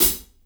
• Sizzle Hi-Hat B Key 06.wav
Royality free pedal hi-hat sound tuned to the B note. Loudest frequency: 9855Hz
sizzle-hi-hat-b-key-06-LQd.wav